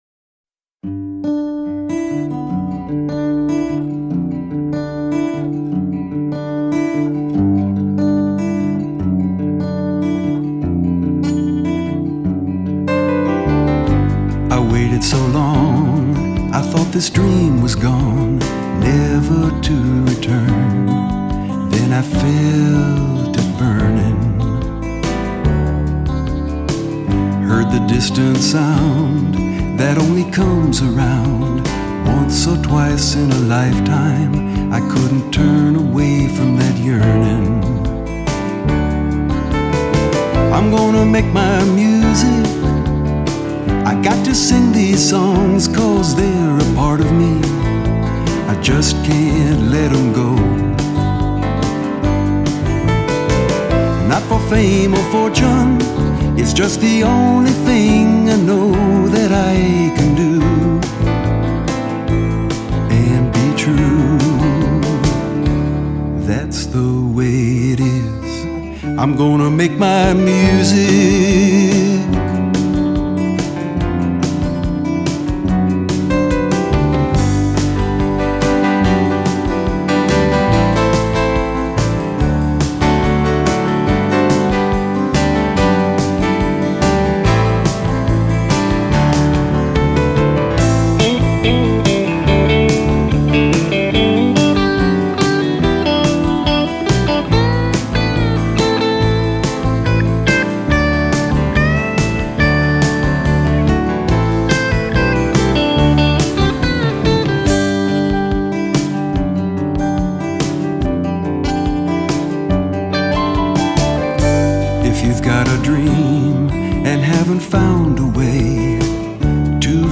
Piano
lead guitar
drums